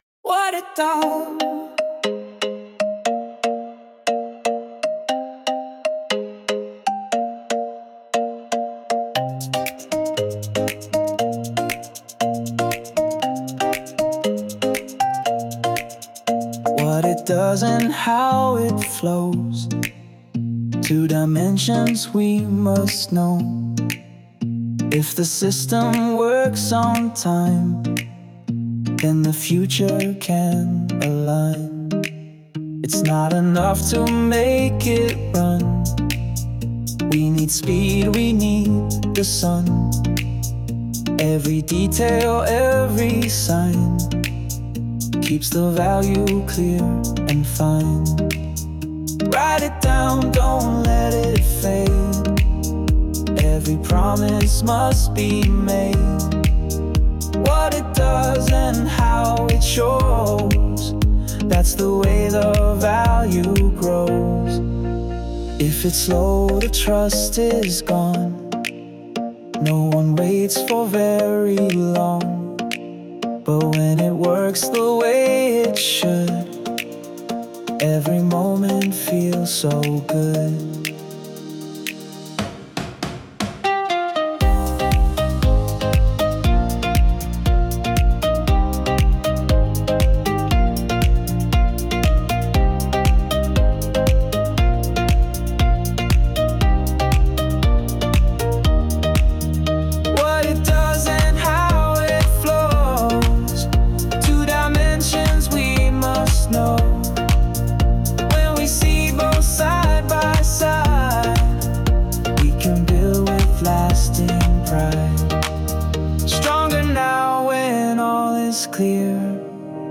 Tropical House · 118 BPM · Eng